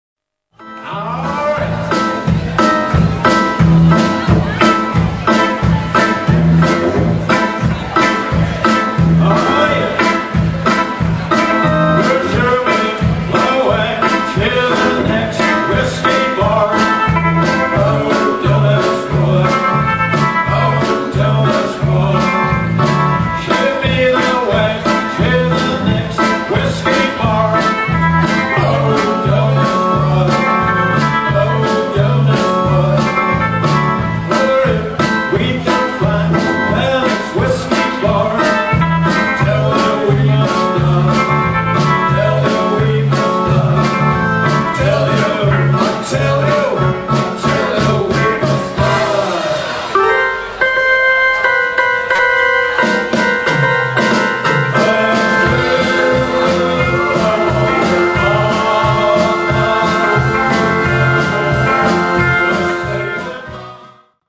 absolutely live mp3-Soundfiles